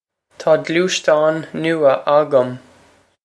Pronunciation for how to say
Taw gloosh-tawn noo-ah a-gum.
This is an approximate phonetic pronunciation of the phrase.